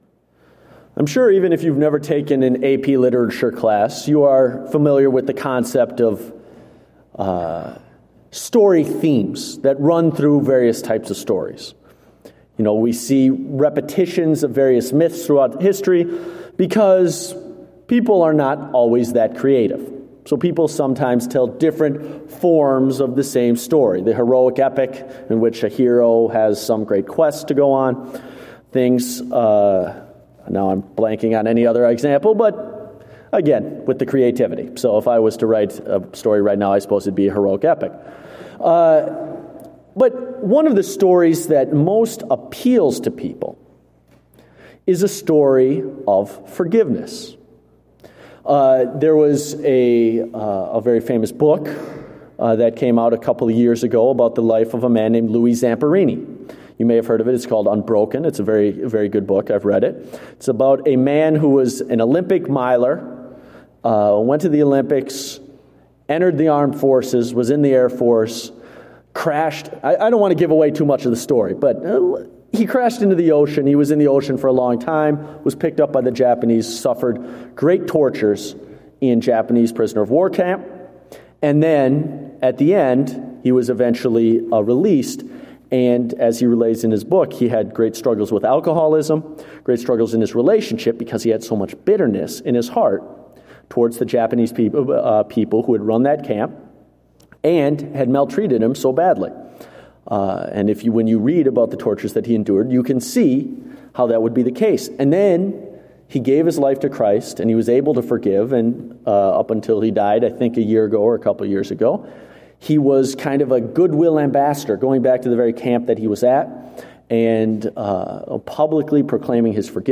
Date: January 24, 2016 (Evening Service)